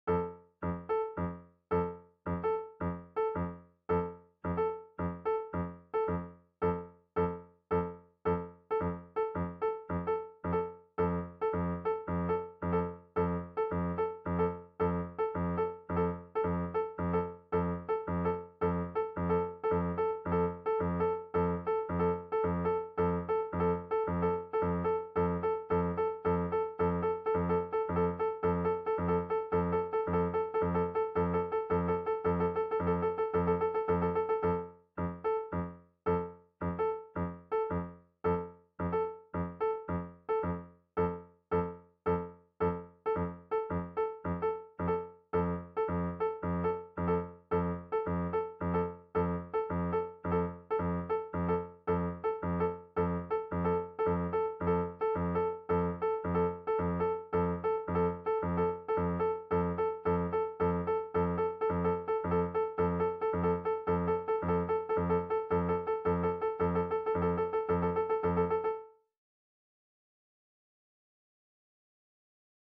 391. Rats accel w Beat